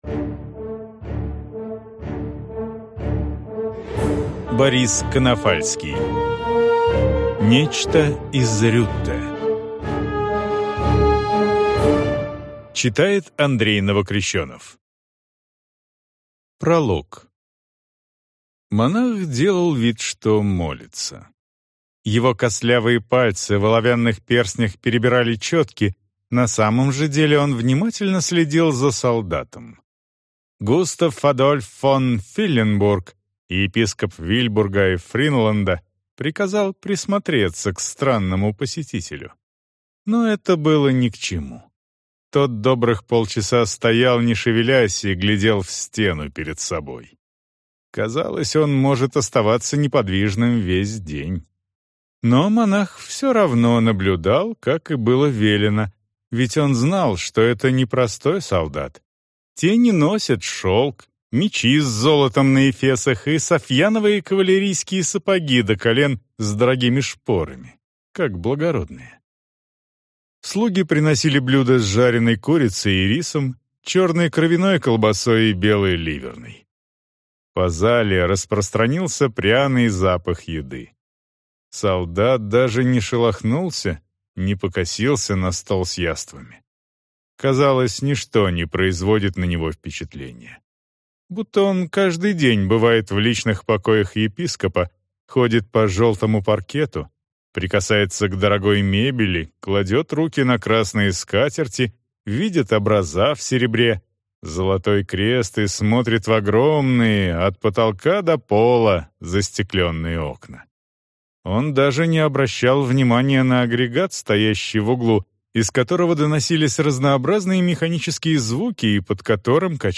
Аудиокнига Нечто из Рютте | Библиотека аудиокниг